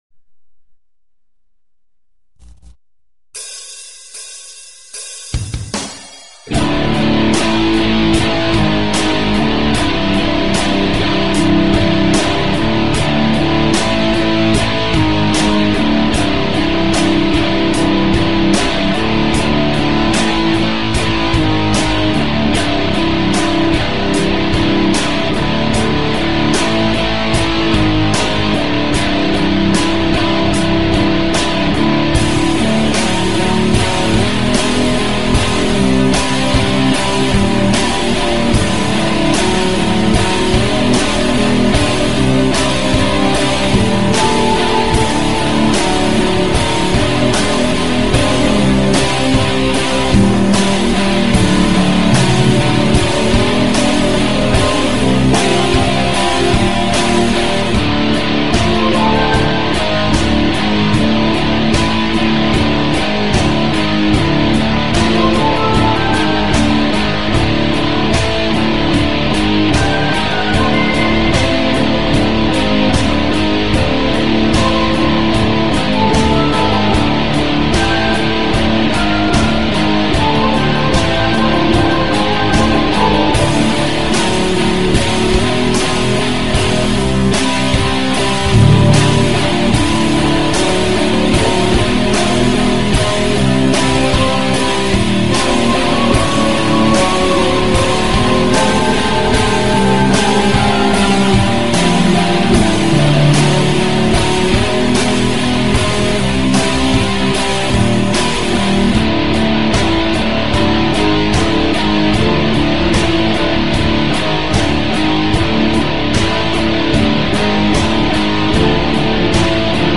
Talk Show Episode, Audio Podcast, The_Crimson_Pill and Courtesy of BBS Radio on , show guests , about , categorized as